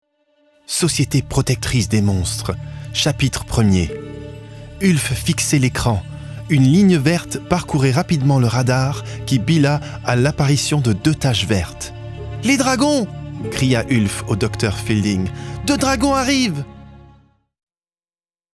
bilingual French and English voice-over actor with a medium, smooth voice that suits 25-35 yo professional, educated male. He is at ease in both languages without a trace of an accent.
Sprechprobe: Sonstiges (Muttersprache):